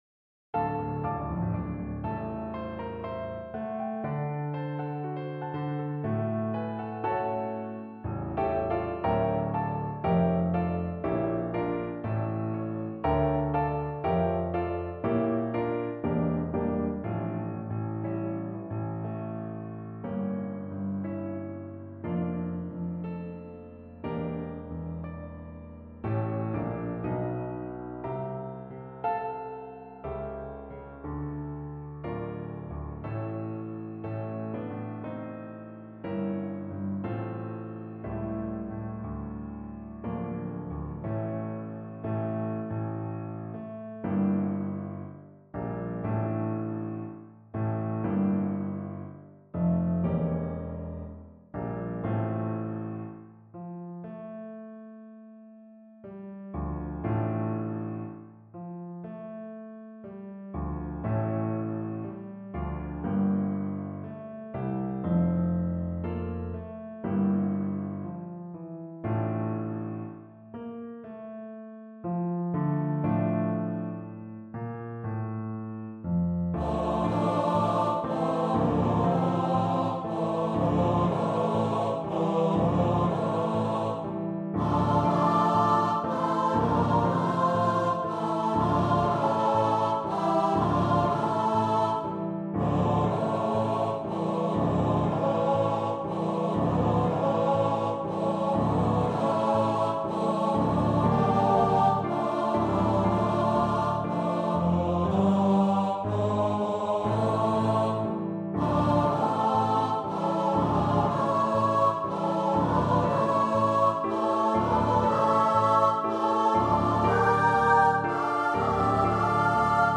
Brahms, Johannes - Gesang der Parzen, Op.89 Free Sheet music for Choir (SATB)
Style: Classical